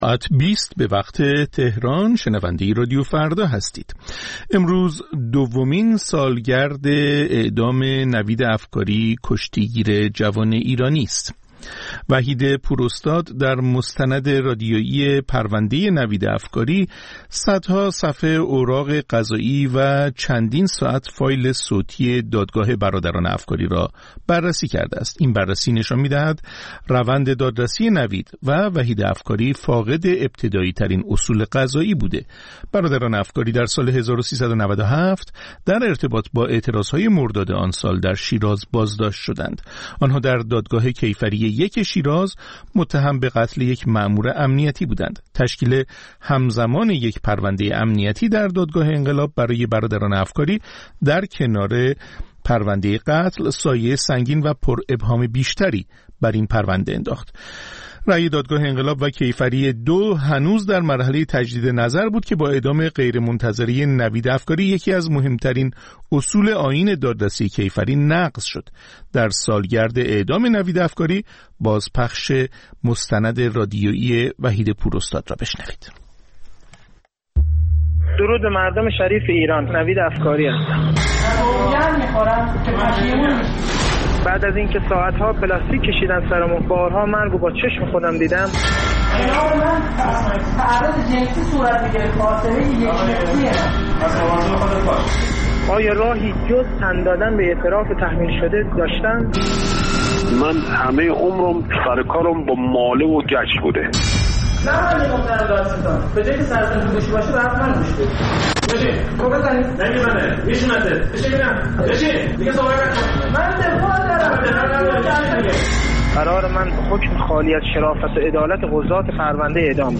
مستند رادیویی: «پرونده نوید افکاری»